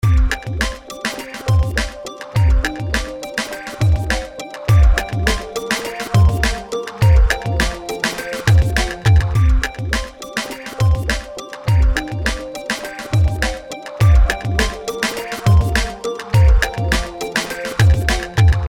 Here is a demonstration of UpStereo. Every four bars are without and with the effect turned on. The sound appears a lot louder and more in the room with the plug-in enabled.
No other effects, enhancement or compression is used in these demos.
UpStereo_4bar_Perc.mp3